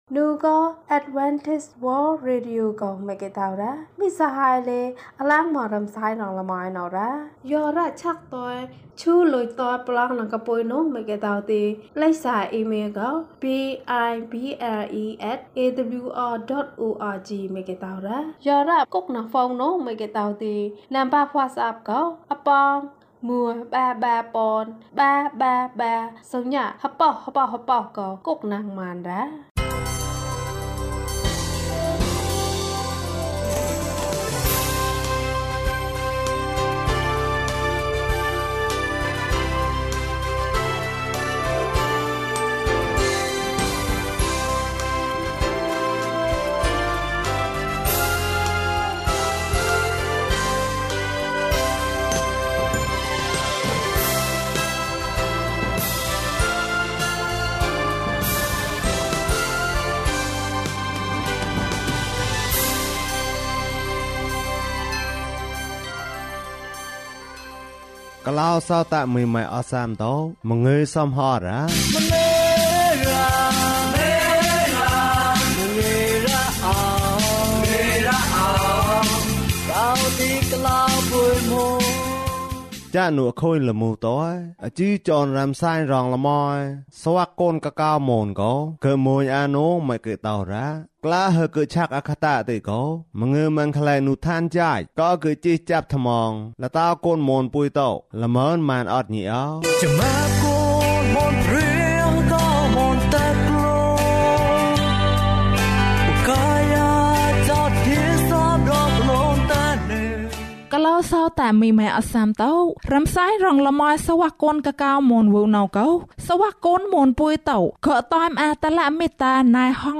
ခရစ်တော်ကို ယုံကြည်ပါ ၀၃။ ကျန်းမာခြင်းအကြောင်းအရာ။ ဓမ္မသီချင်း။ တရားဒေသနာ။